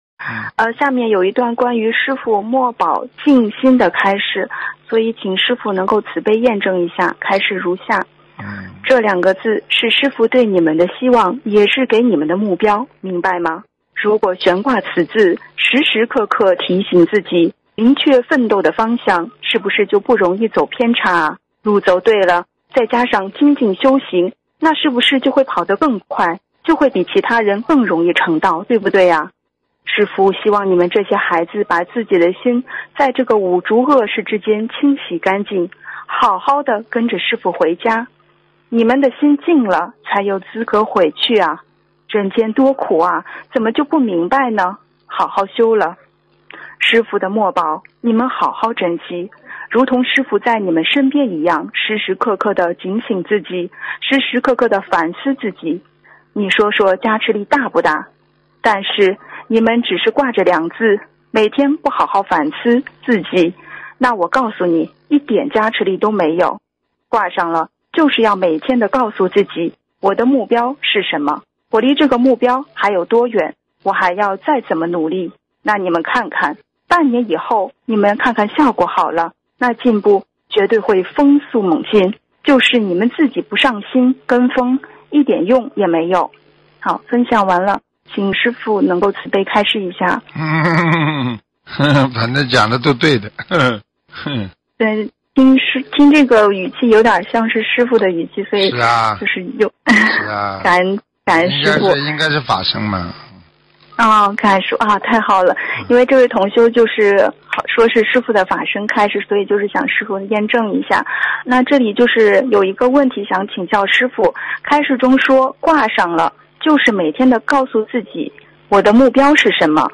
音频：关于师父墨宝《净心》的开示！